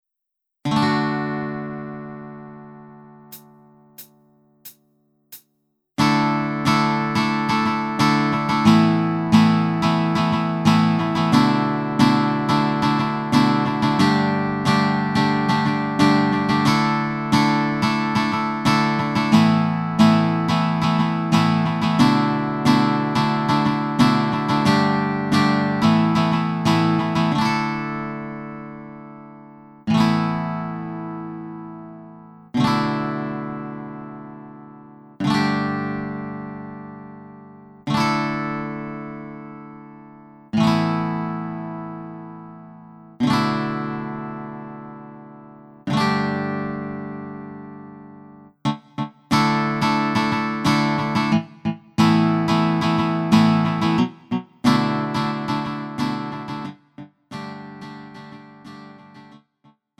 음정 원키 3:59
장르 가요 구분 Lite MR